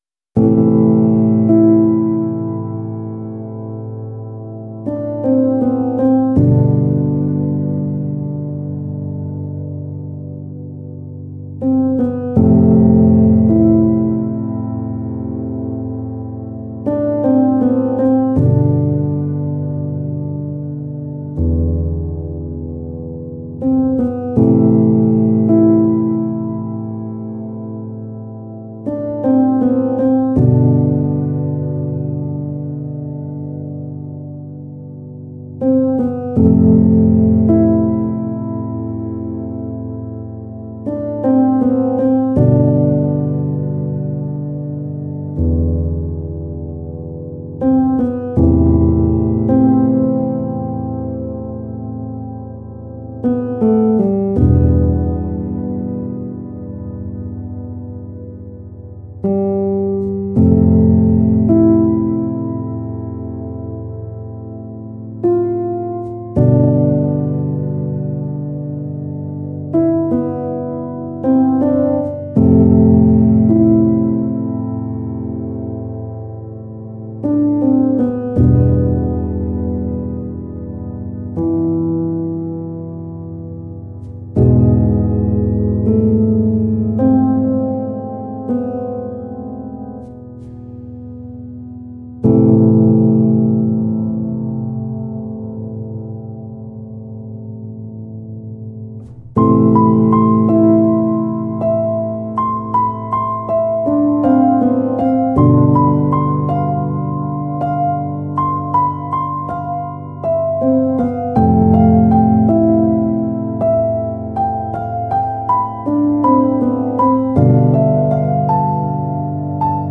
PIANO Q-S (31)